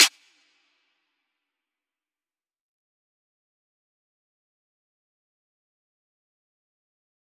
Snares
DMV3_Snare 7.wav